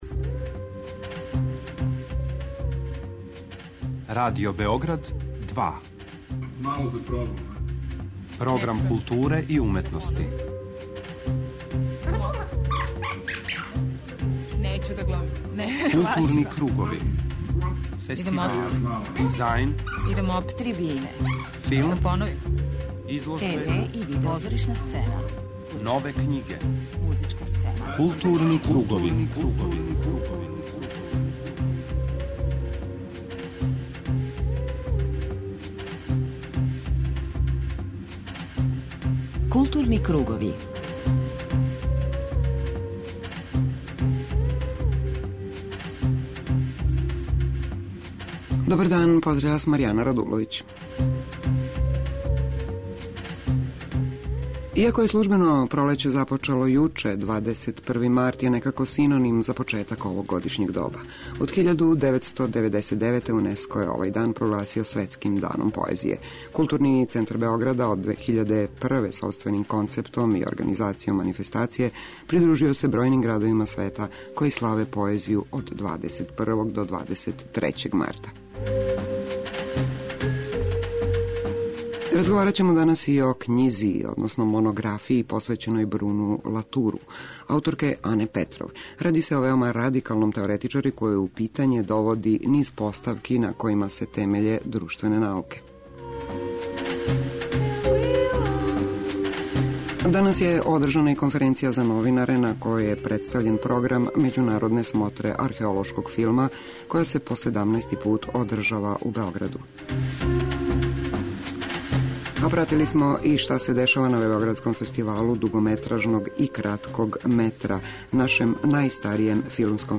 преузми : 41.00 MB Културни кругови Autor: Група аутора Централна културно-уметничка емисија Радио Београда 2.